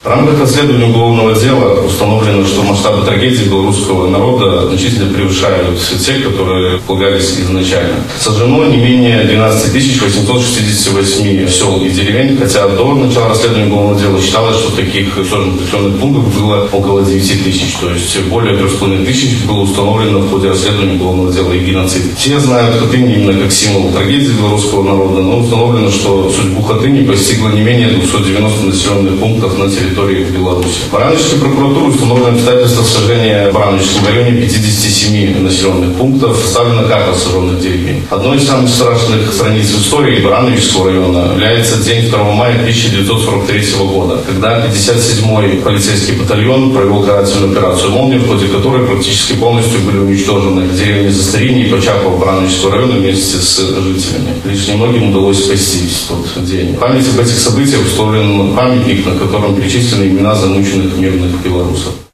Здесь состоялось городское мероприятие, посвященное памяти Хатынской трагедии.